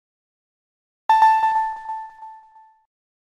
Nintendo_DS_Start-Up_Screen.mp3